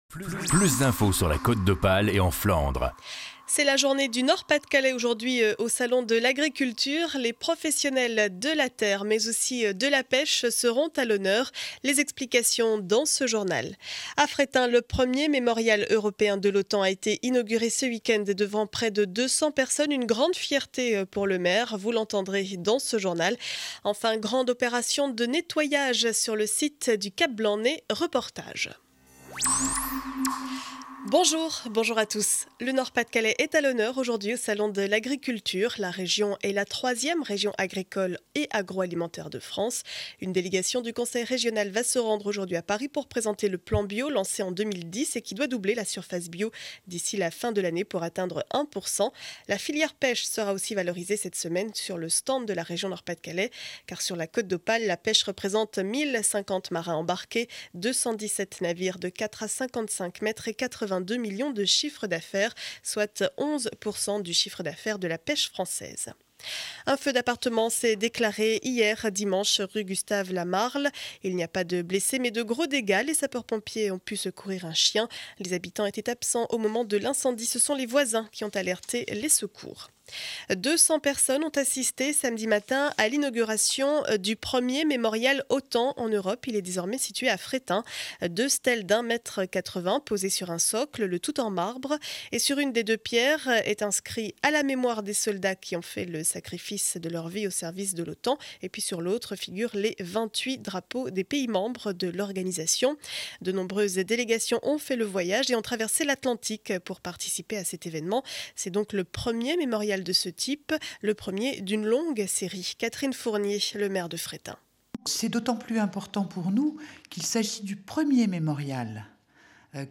Journal du lundi 27 février 2012 7 heures 30 édition du Calaisis.